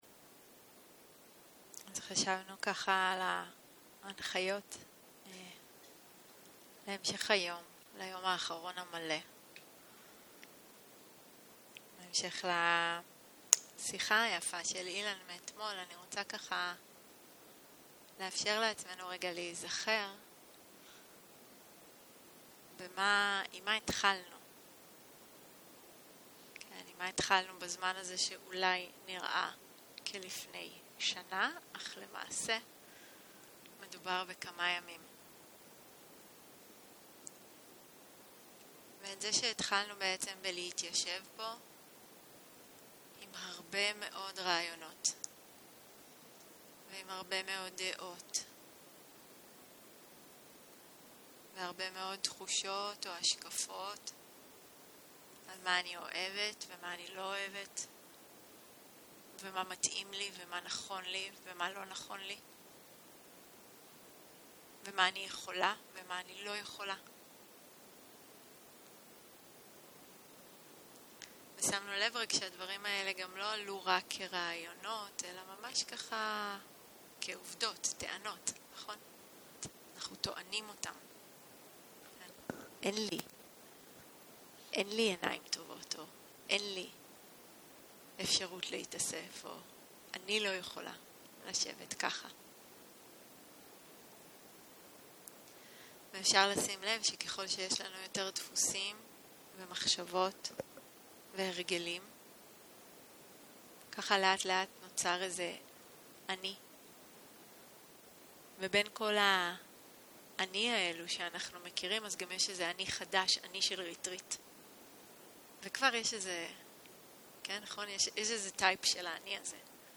יום 6 - בוקר - הנחיות מדיטציה - תופעות בעולם, אנאטה - הקלטה 14 0:00 0:00 סוג ההקלטה: Dharma type: Guided meditation שפת ההקלטה: Dharma talk language: Hebrew